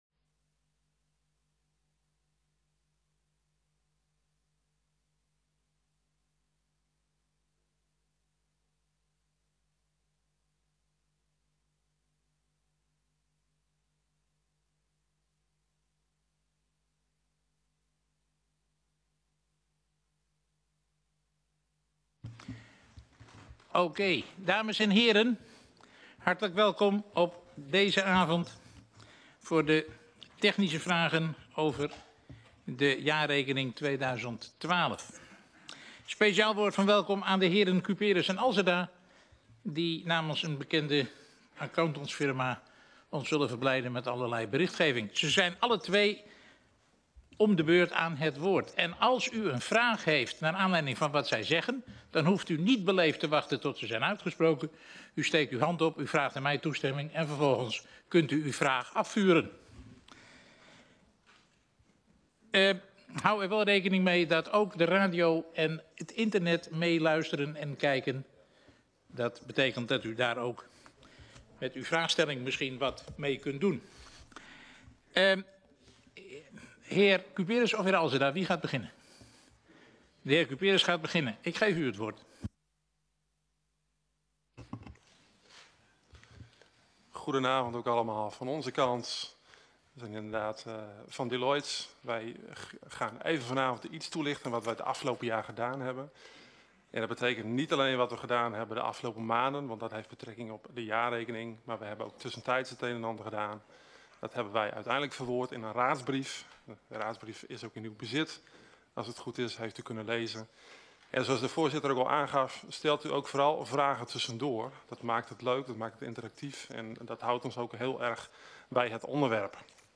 Locatie: Raadszaal